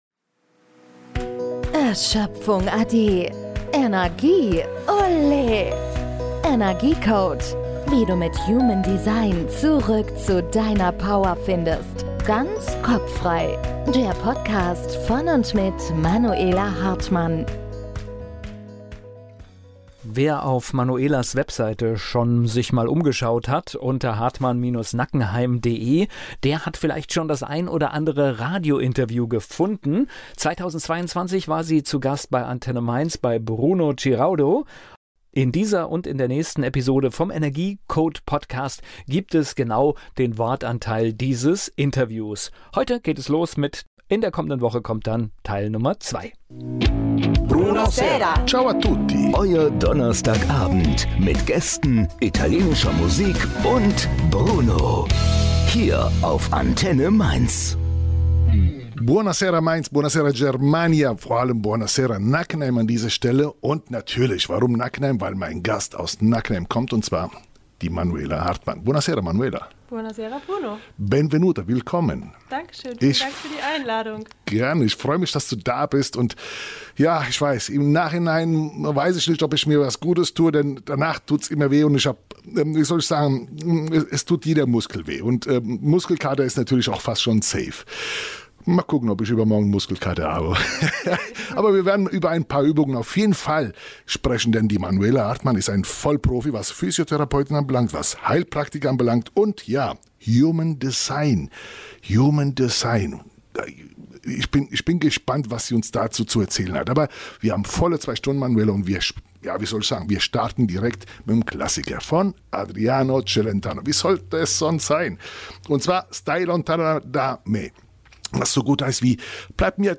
Energie-Code - Zu Gast bei ANTENNE MAINZ Teil 1
Interview